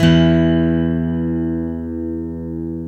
GTR 12 STR0F.wav